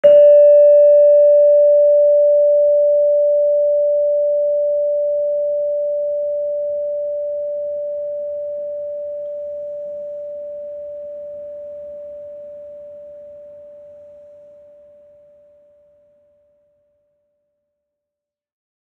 HSS-Gamelan-1
Gender-2-D4-f.wav